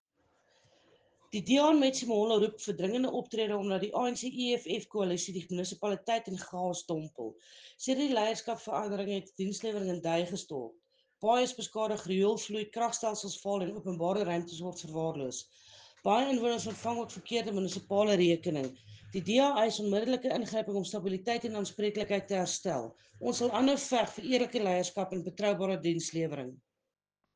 Afrikaans soundbites by Cllr Linda Day and